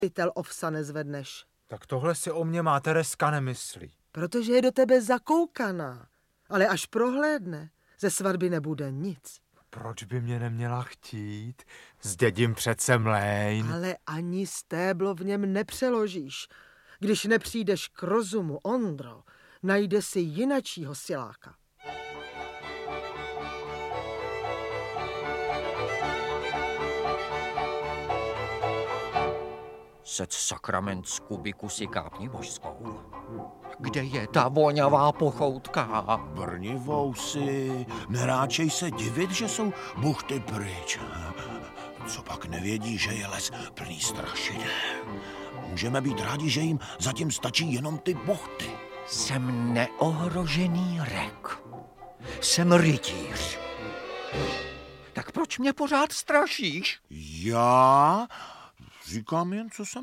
Audiobook
Read: Marek Eben